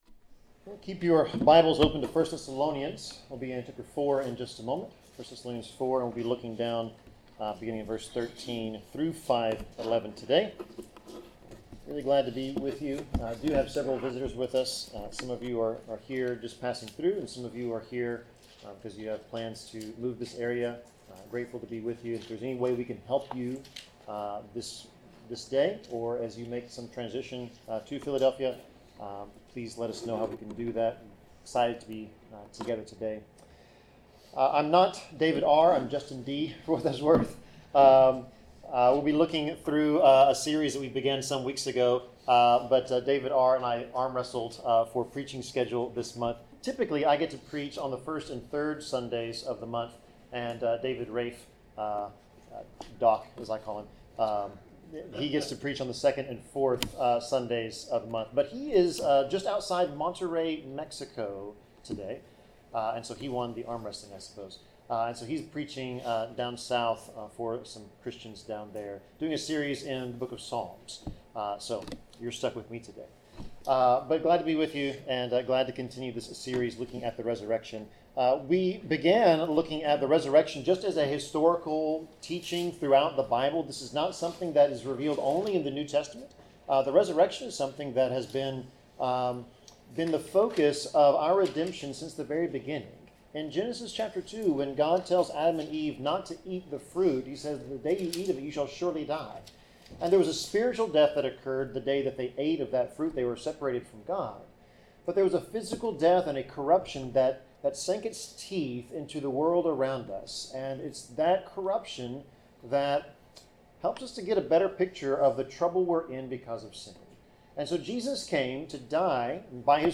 Service Type: Sermon